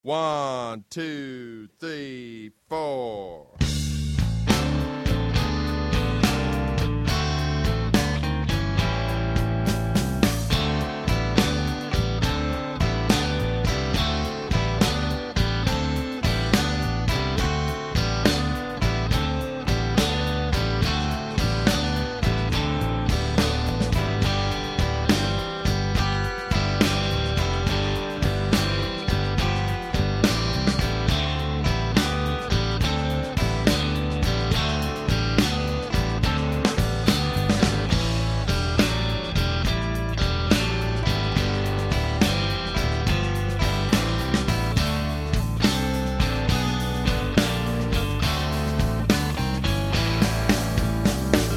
Genre blues rock
• Instrumentation : Guitare